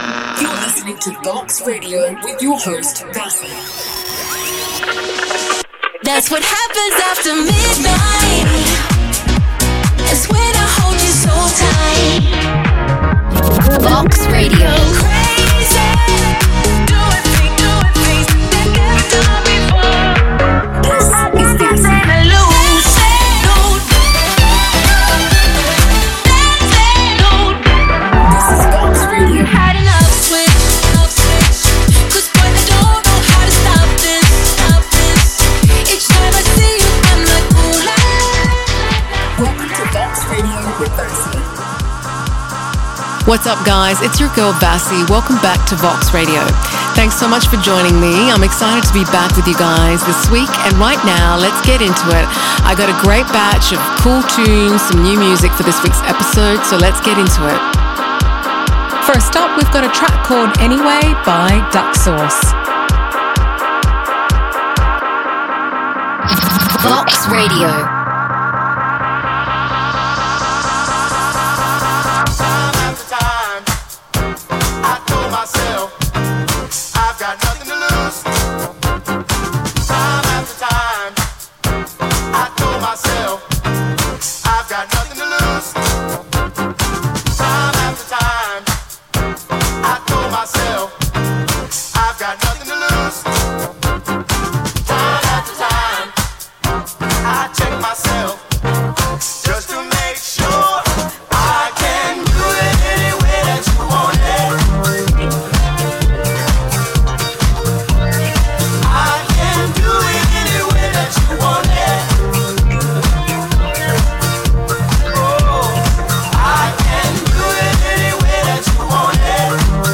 known for her powerhouse vocals
a high-energy hour of house music